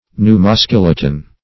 Search Result for " pneumoskeleton" : The Collaborative International Dictionary of English v.0.48: Pneumoskeleton \Pneu`mo*skel"e*ton\, n. [Pneumo- + skeleton.]